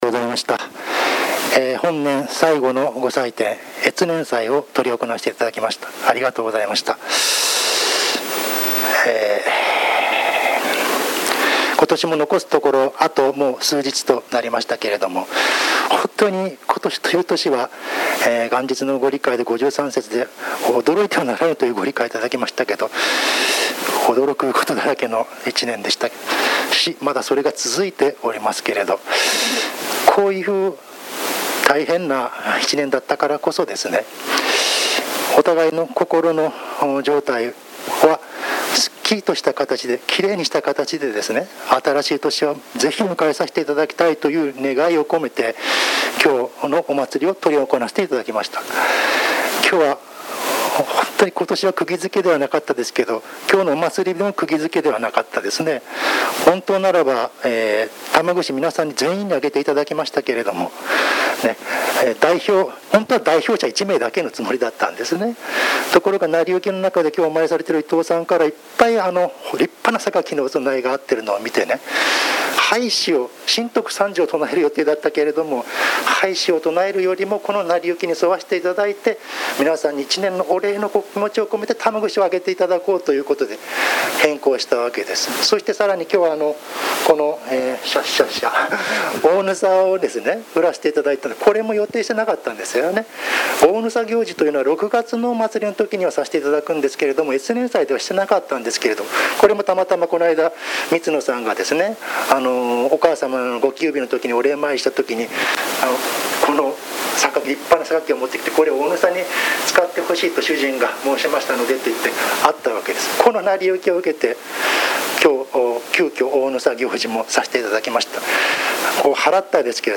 越年祭教話